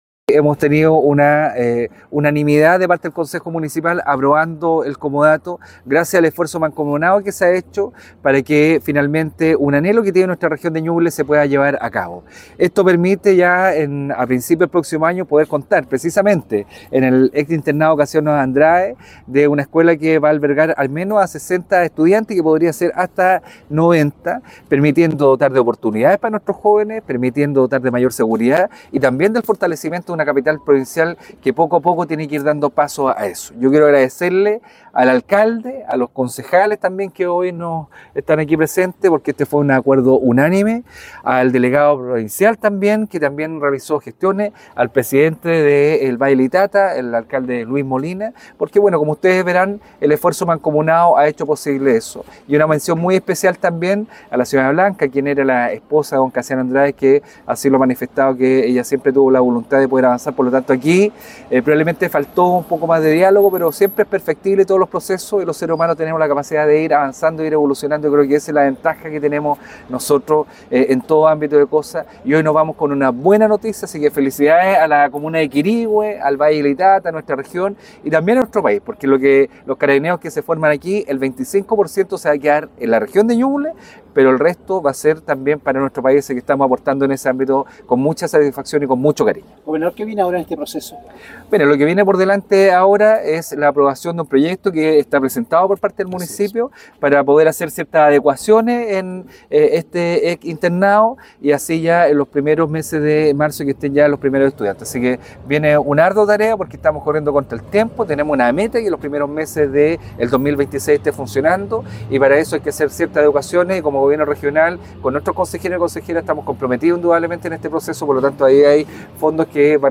Gobernador-Punto-de-prensa-ok.mp3